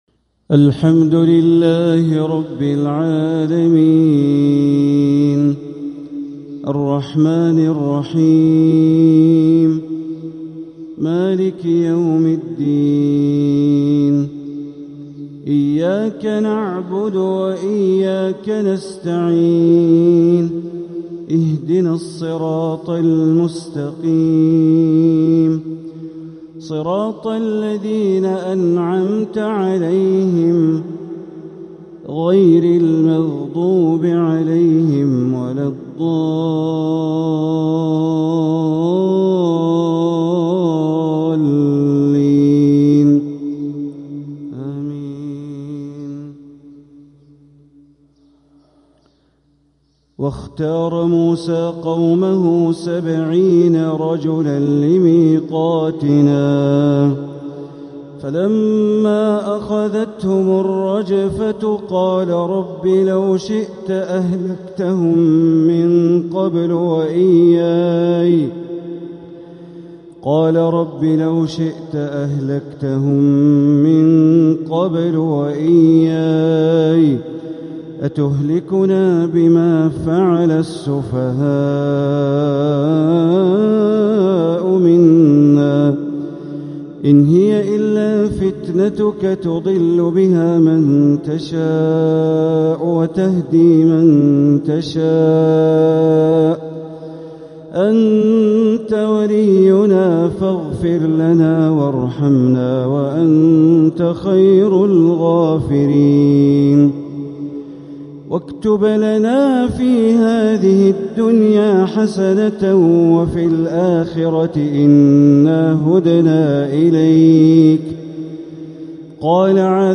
تلاوة من سورة الأعراف ١٥٥-١٦٠ | فجر الجمعة ٣٠ جمادى الأولى ١٤٤٧ > 1447هـ > الفروض - تلاوات بندر بليلة